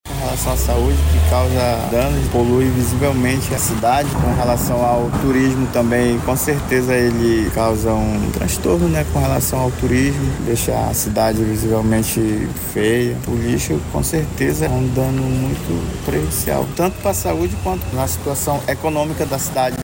conforme destaca o policial militar